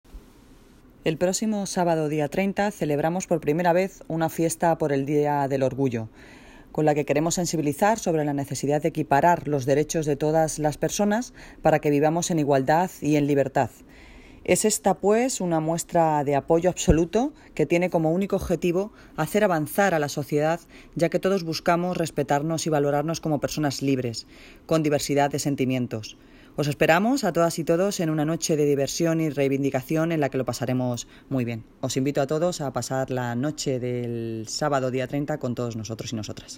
Audio - Noelia Posse (Alcaldesa de Móstoles) Sobre Declaración Institucional con motivo del Día LGTB